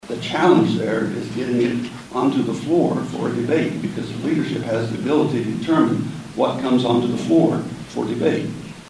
MANHATTAN — Hurdles to Medicaid expansion were a major highlight of Saturday’s legislative coffee held at the Sunset Zoo Nature Exploration Place.
Hospitals have lobbied for expansion as well but conservative leadership in the statehouse has been content on keeping any bill from coming to the floor for debate. 67th District Representative Tom Phillips (R-Manhattan) says he believes it has enough momentum among both parties.